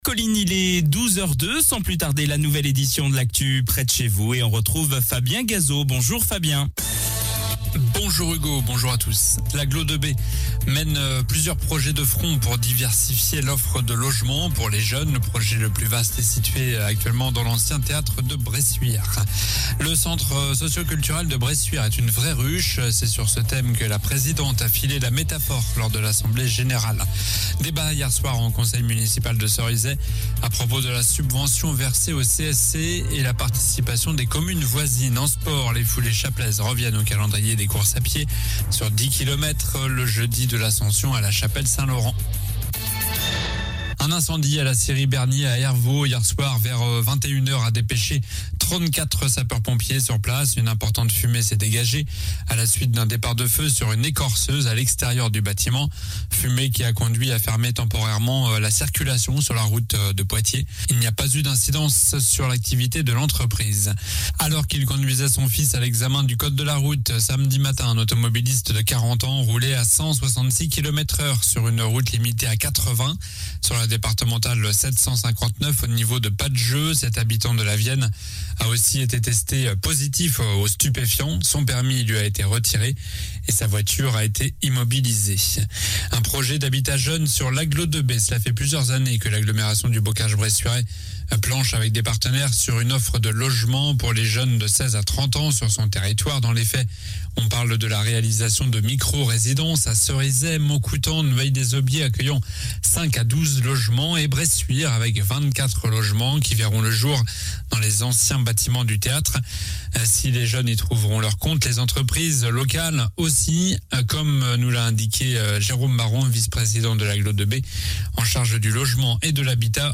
Journal du mardi 24 mai (midi)